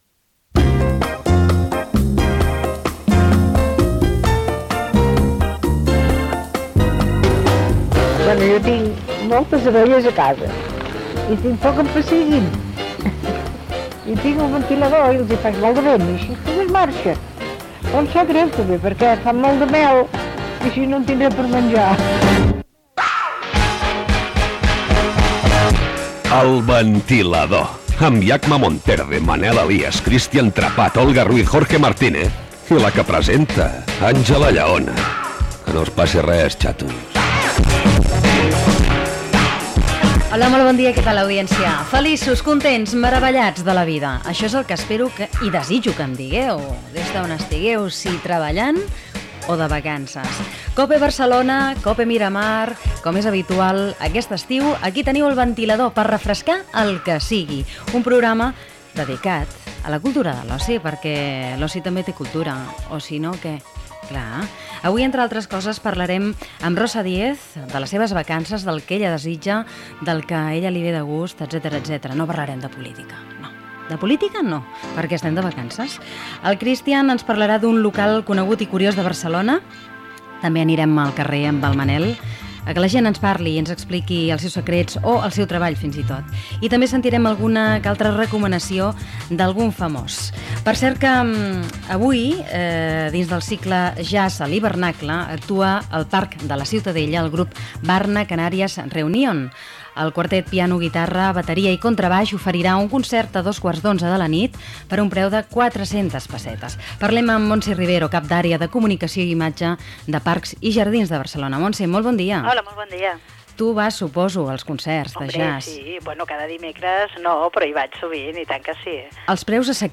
Careta d'entrada, presentació, sumari, concert de jazz a L'hivernacle del Parc de la Ciutadella de Barcelona, publicitat, exposició al MACBA, entrevista a la parlemantària europea Rosa Díez, publicitat, concurs Gènere radiofònic Entreteniment